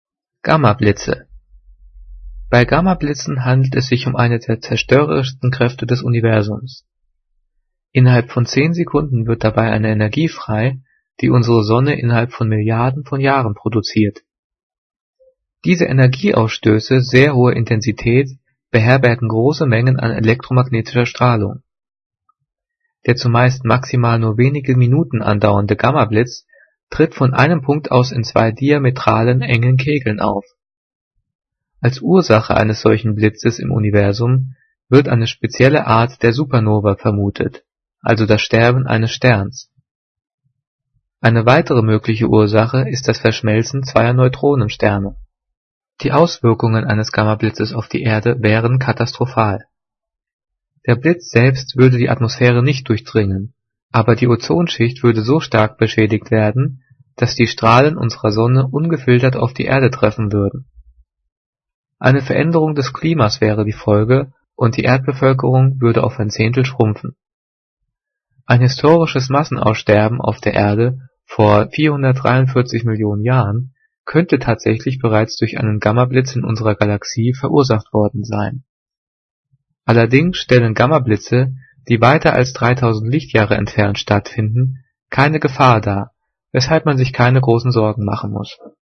Diktat: "Gammablitze" - 7./8. Klasse - Fremdwörter
Gelesen:
gelesen-gammablitze.mp3